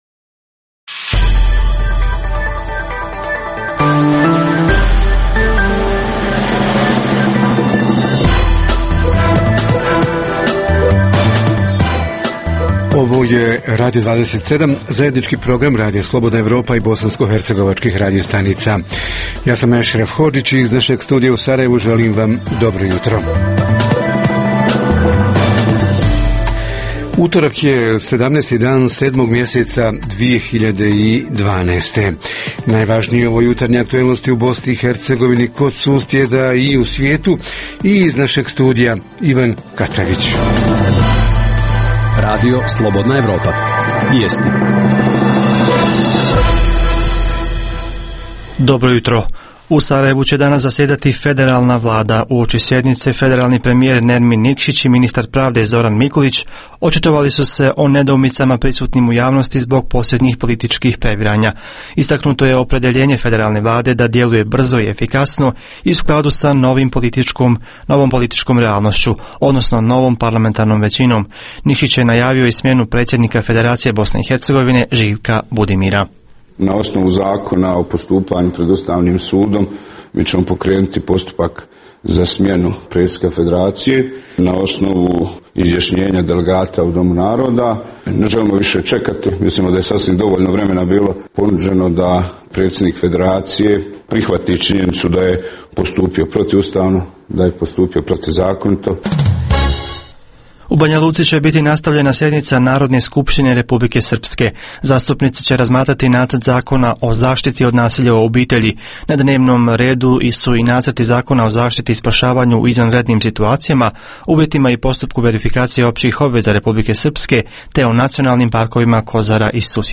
Tema našeg jutarnjeg programa: javne službe i ustanove u sezoni godišnjih odmora – ima li čekanja u dugim redovima – kako su se organizirali da građani ne trpe? Reporteri iz cijele BiH javljaju o najaktuelnijim događajima u njihovim sredinama.
Redovni sadržaji jutarnjeg programa za BiH su i vijesti i muzika.